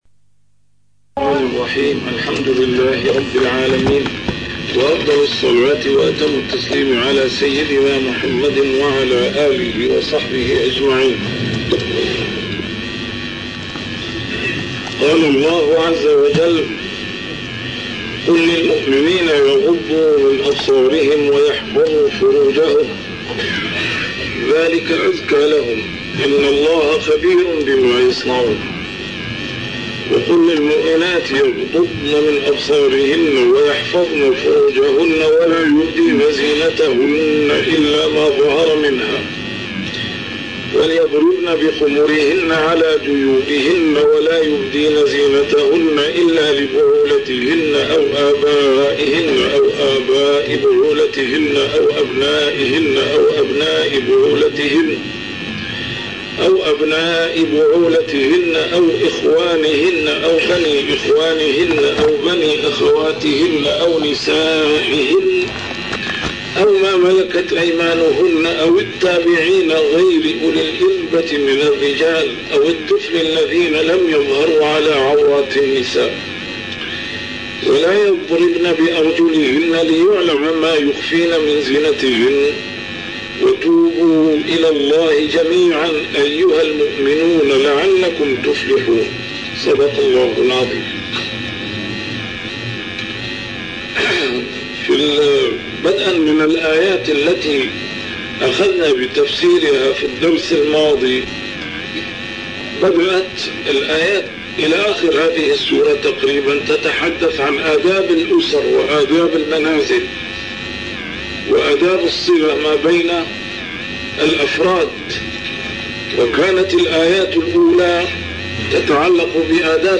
A MARTYR SCHOLAR: IMAM MUHAMMAD SAEED RAMADAN AL-BOUTI - الدروس العلمية - تفسير القرآن الكريم - تسجيل قديم - الدرس 184: النور 30-31